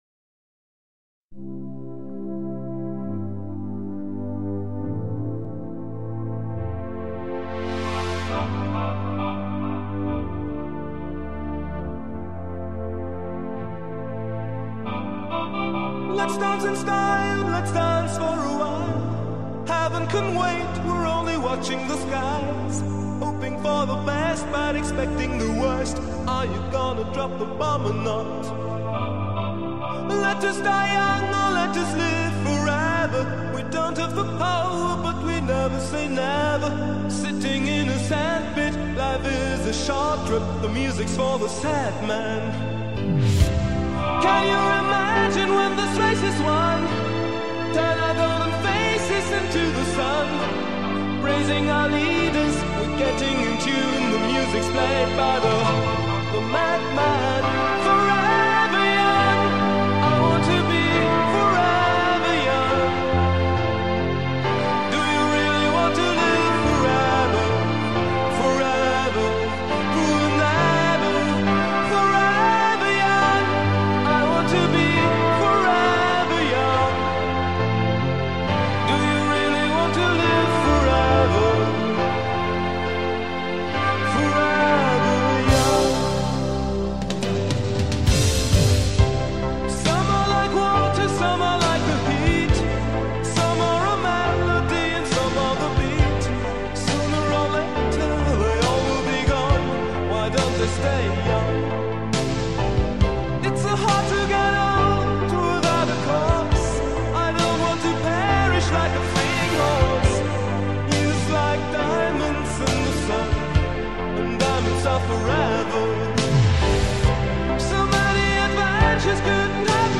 #8dmusic #8daudio #8d